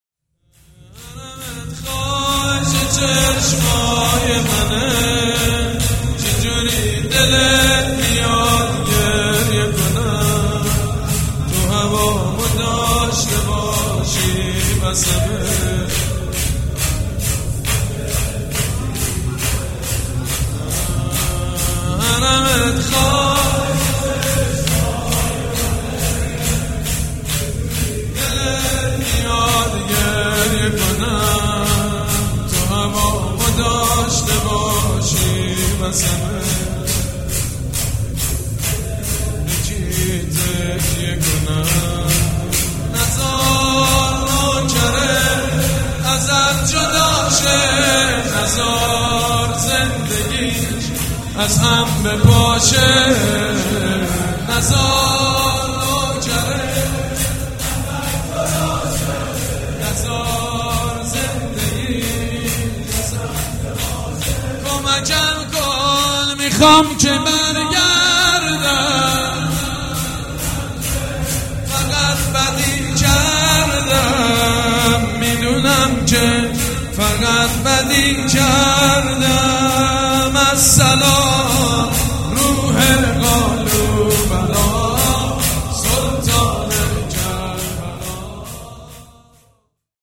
شور
حاج سید مجید بنی فاطمه
وفات حضرت زینب (س)
شور چهارم.mp3